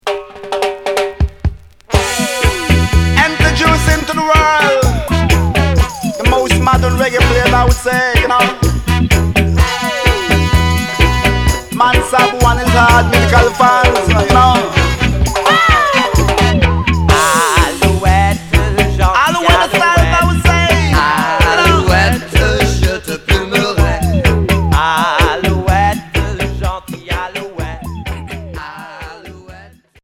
BO reggae